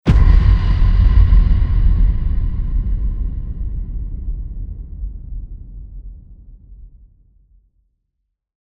Sound Effects Library. Impact (CC BY)
sound-effects-library-impact.mp3